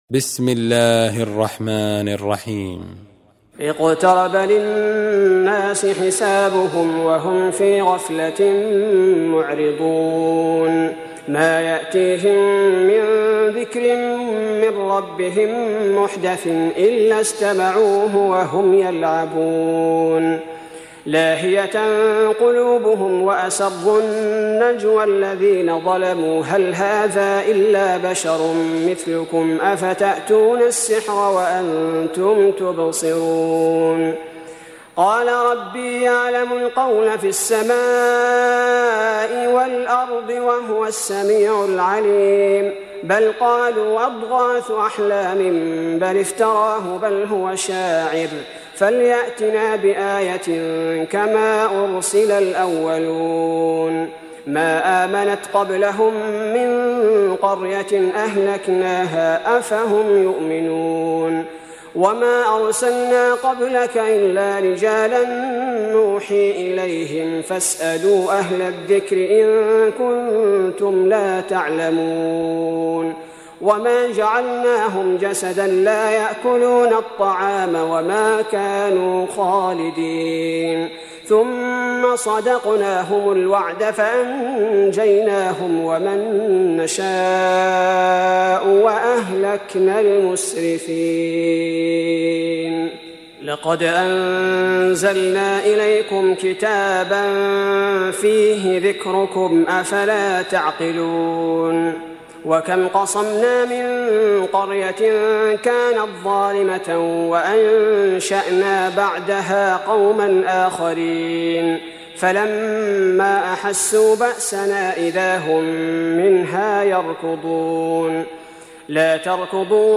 Surah Repeating تكرار السورة Download Surah حمّل السورة Reciting Murattalah Audio for 21. Surah Al-Anbiy�' سورة الأنبياء N.B *Surah Includes Al-Basmalah Reciters Sequents تتابع التلاوات Reciters Repeats تكرار التلاوات